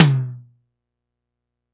Tom High.wav